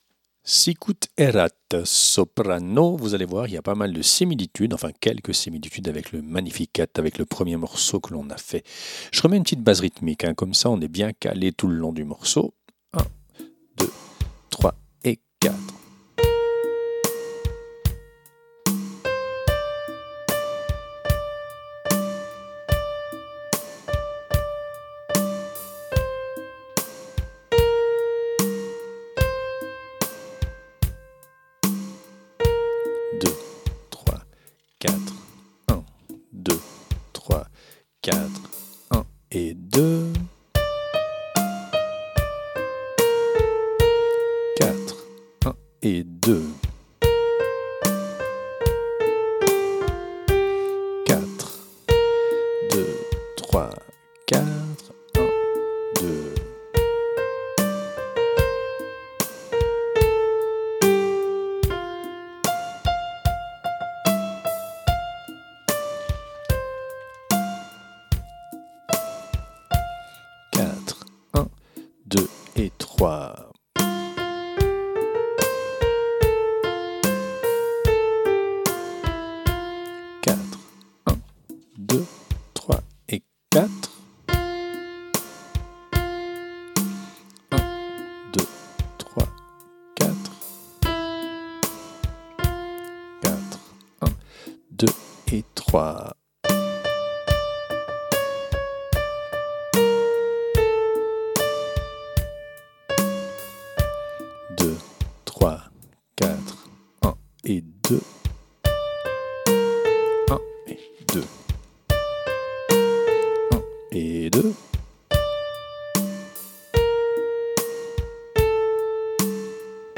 Répétition SATB par voix
Soprane
Sicut Erat Soprano.mp3